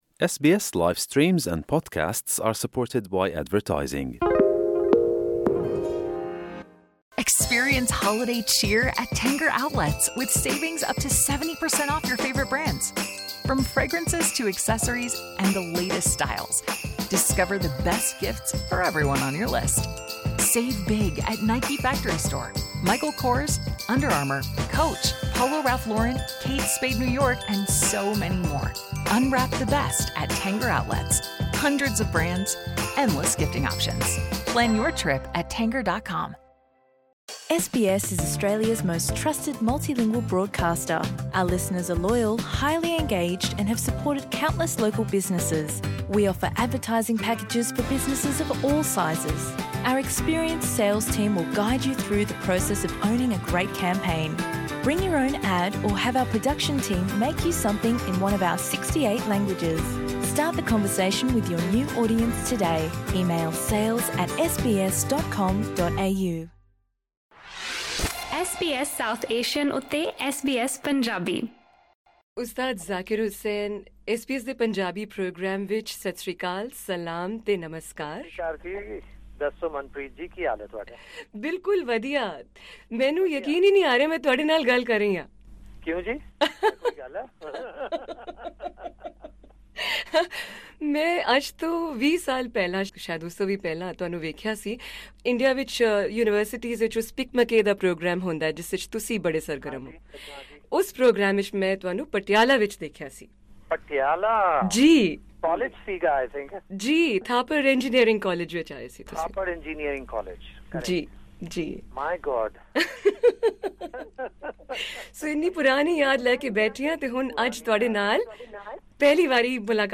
ਐਸ ਬੀ ਐਸ ਪੰਜਾਬੀ ਵਲੋਂ ਭਾਰਤੀ ਮੂਲ ਦੇ ਵਿਸ਼ਵ ਪ੍ਰਸਿੱਧ ਤਬਲਾ ਵਾਦਕ ਉਸਤਾਦ ਜ਼ਾਕਿਰ ਹੁਸੈਨ ਨਾਲ ਸਾਲ 2011 ਵਿੱਚ ਇੱਕ ਇੰਟਰਵਿਊ ਪੰਜਾਬੀ ਜ਼ੁਬਾਨ ਵਿੱਚ ਰਿਕਾਰਡ ਕੀਤੀ ਗਈ ਸੀ, ਜਿਸ ਵਿੱਚ ਉਨ੍ਹਾਂ ਨੇ ਆਪਣੀ ਪੰਜਾਬੀ ਪਿਛੋਕੜ ਅਤੇ ਪੰਜਾਬ ਨਾਲ ਸੰਗੀਤਕ ਸਾਂਝ ਬਾਰੇ ਬੇਹੱਦ ਰੌਚਕ ਅਤੇ ਹੈਰਾਨੀਜਨਕ ਪੱਖ ਸਾਂਝੇ ਕੀਤੇ ਸਨ।